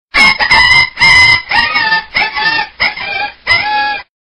hurt-dog.mp3